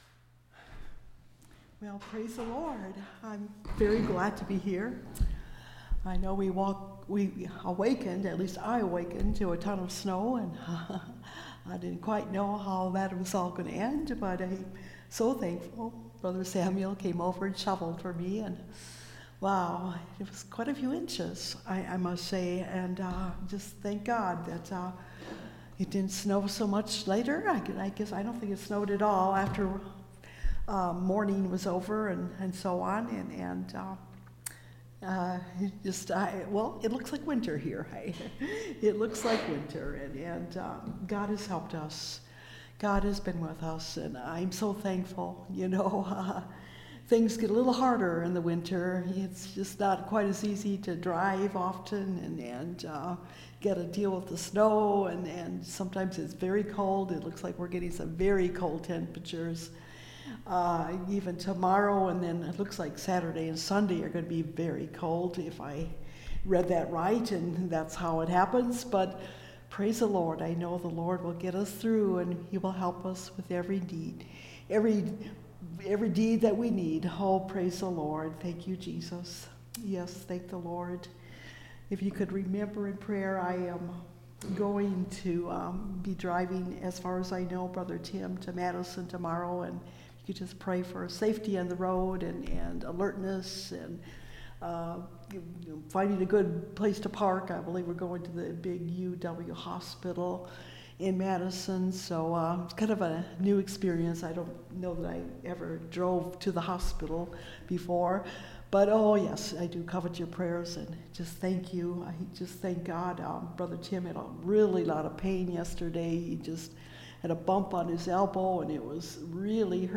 O Give Thanks Unto The Lord (Part 3) (Message Audio) – Last Trumpet Ministries – Truth Tabernacle – Sermon Library